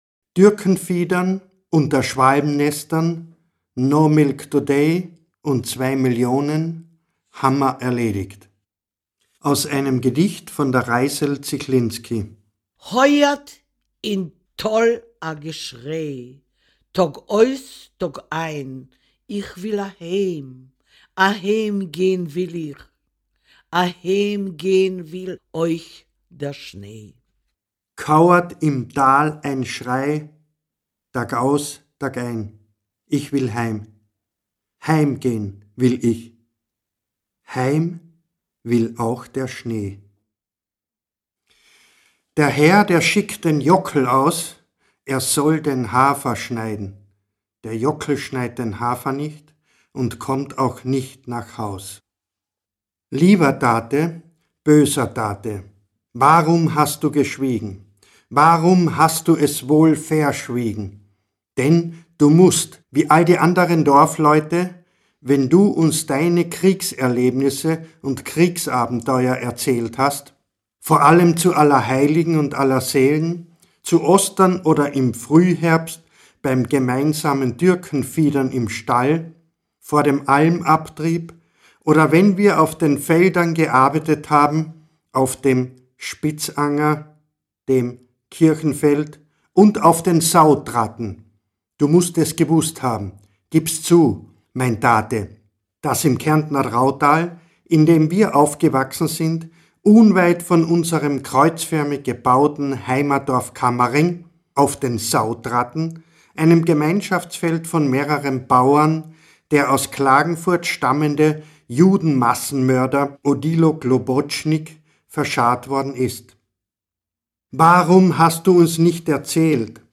Ungekürzte Autoren - Lesung
Josef Winkler (Sprecher)